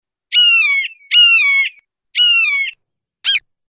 Звуки ястреба-воробья